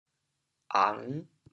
潮州 ang5 hê5 潮阳 ang5 hê5 潮州 0 1 潮阳 0 1